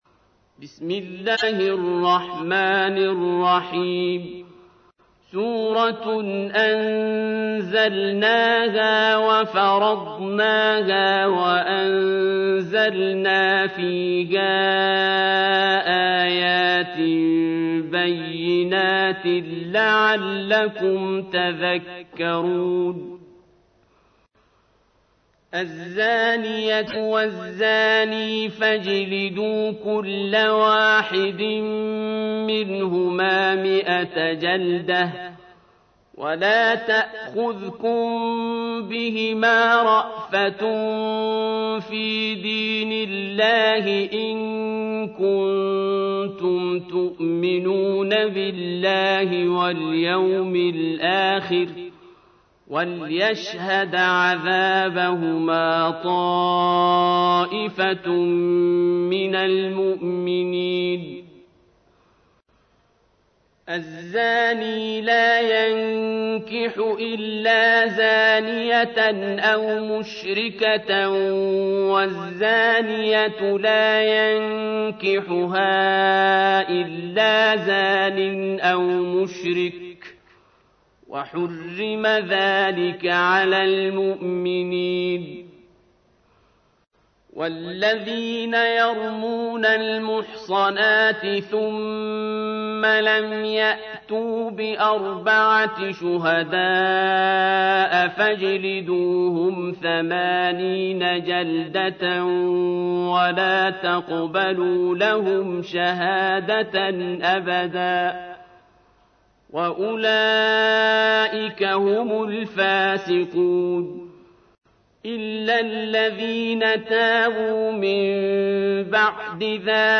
تحميل : 24. سورة النور / القارئ عبد الباسط عبد الصمد / القرآن الكريم / موقع يا حسين